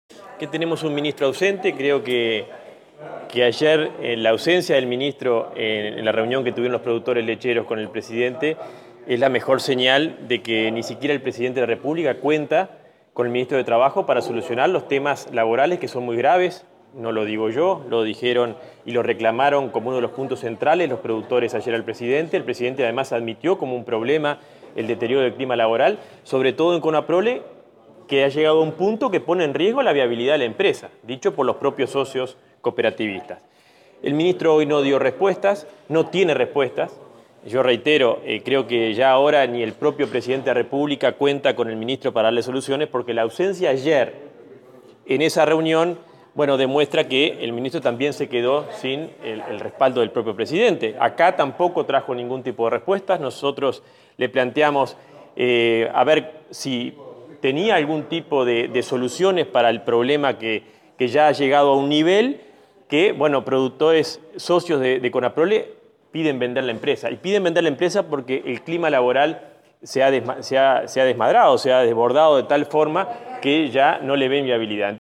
Un duro intercambio por la situación de Conaprole se produjo en la mañana de este miércoles en la Comisión de Industria de Diputados en el marco de la comparecencia del ministro de Trabajo, Ernesto Murro.